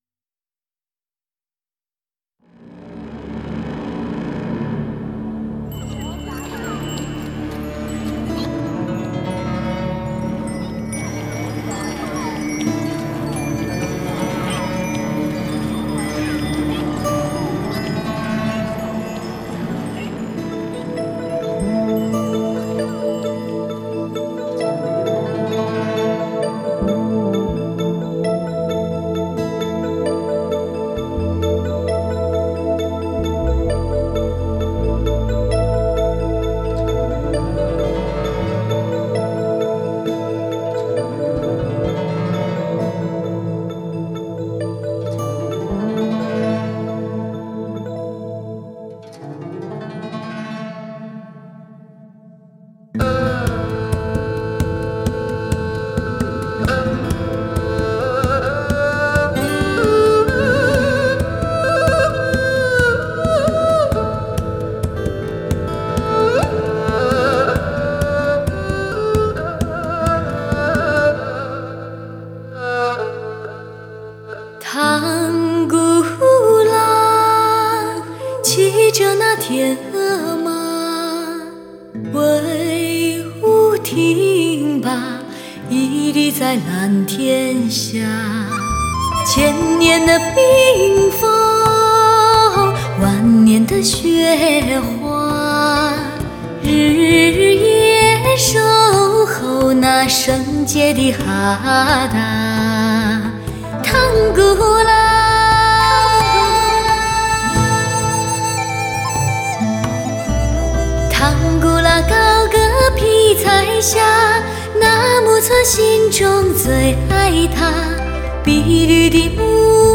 3周前 华语音乐 9